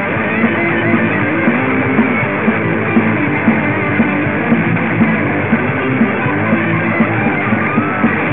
Punkarra